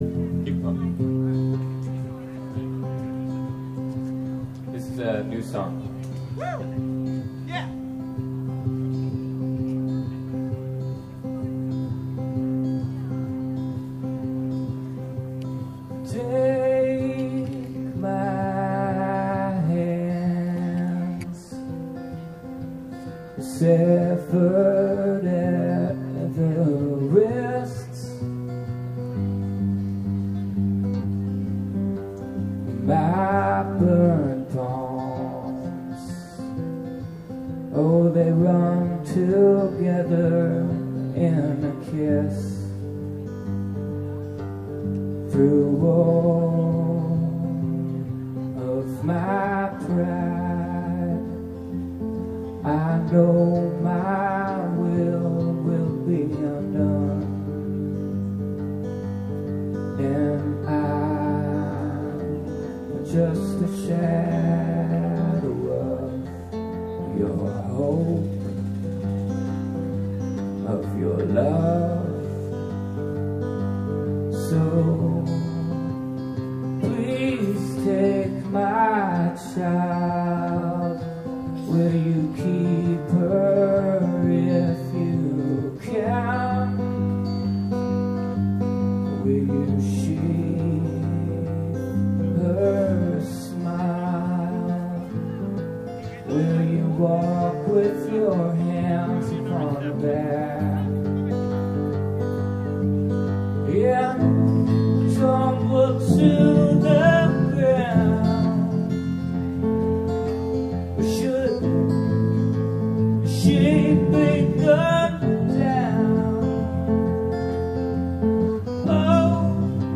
live at NSIT O-Fest 9-17-05